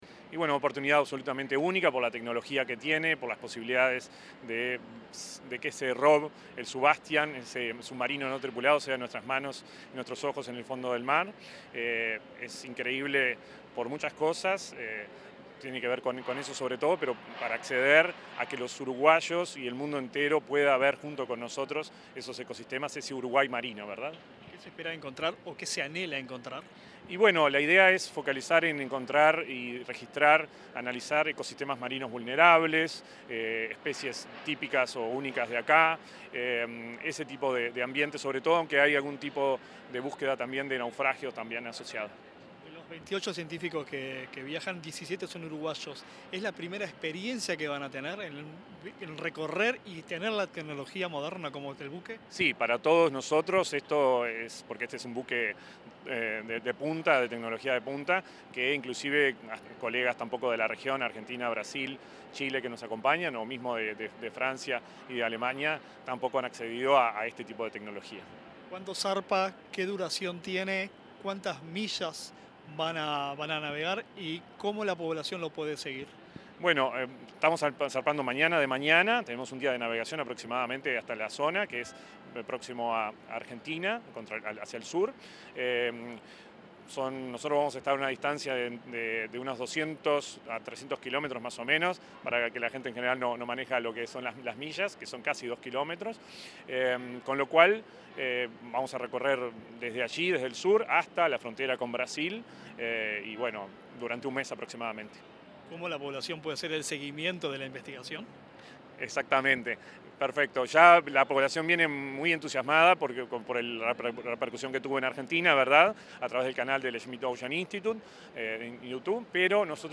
Declaraciones
Tras finalizar la visita del presidente de la República, Yamandú Orsi, a la embarcación de la campaña científica Uruguay Sub-200.